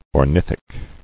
[or·nith·ic]